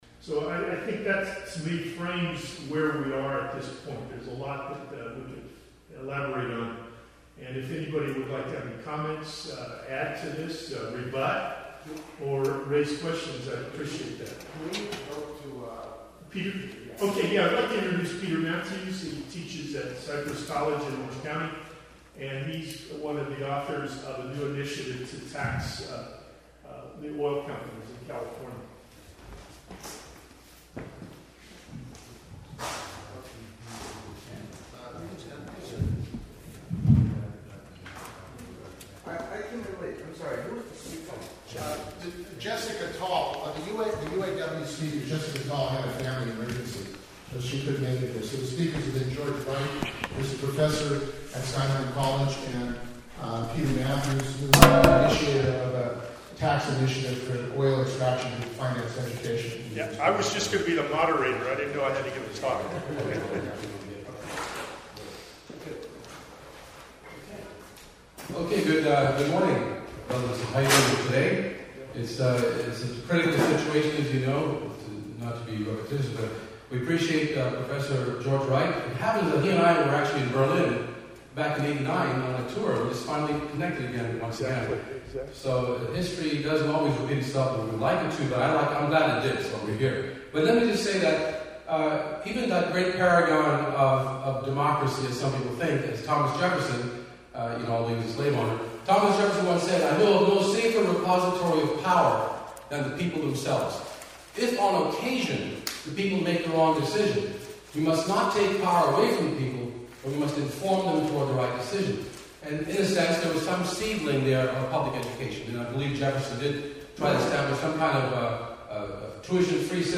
LaborFest 2011 - Tax Big Oil To Fund California Education